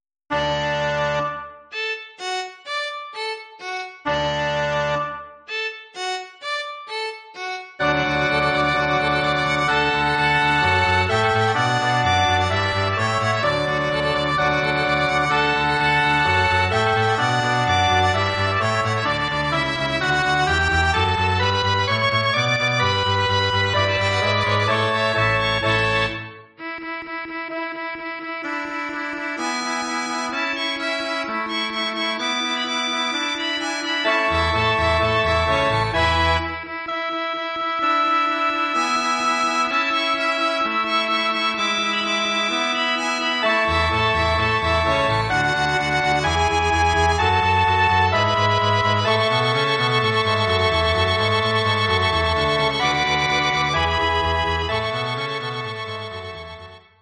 Bearbeitung für Nonett
arrangement for nonet